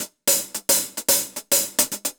Index of /musicradar/ultimate-hihat-samples/110bpm
UHH_AcoustiHatB_110-05.wav